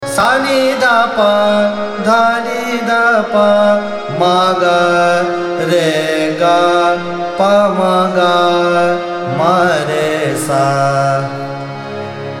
Raga
Ahlaiya Bilawal is characterized by its serene, devotional, and uplifting mood.
Avaroha – ‘S- n D P, D n D P, m G- R G P- m G- m R S